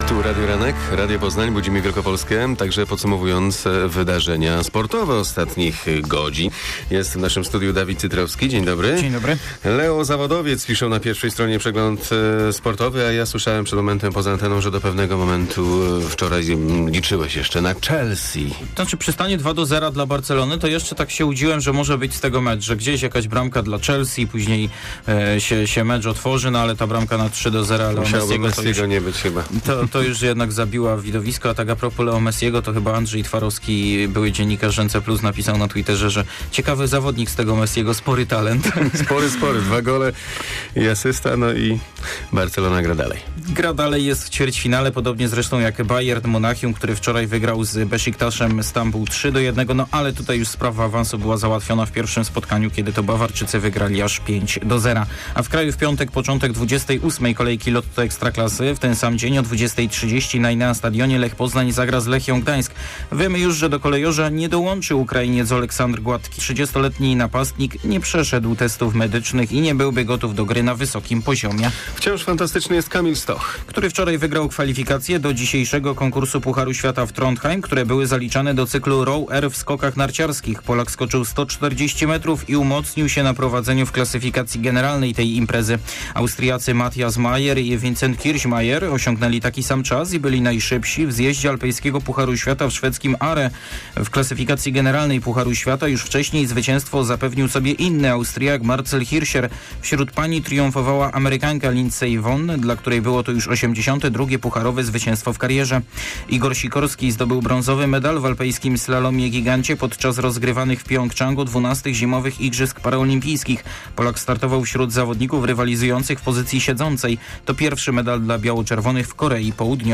15.03 serwis sportowy godz. 7:45